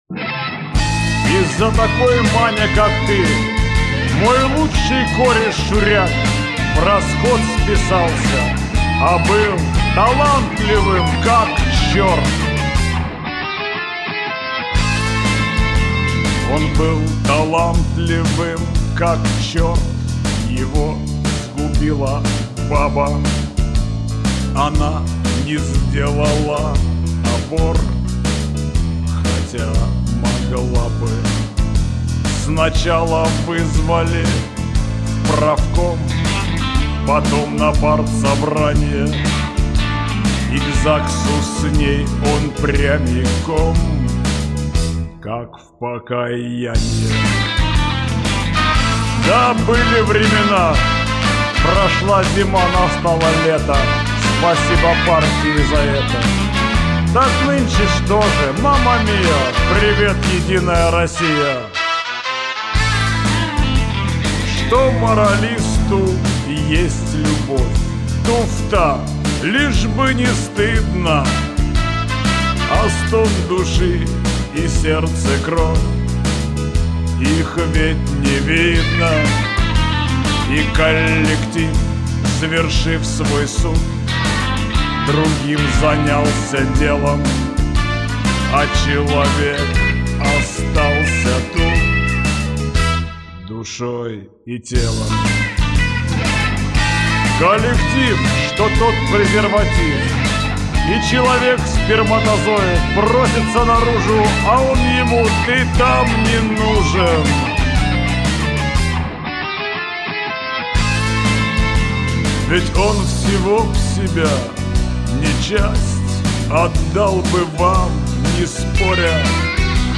Вот нашел эту песню, но только моно запись.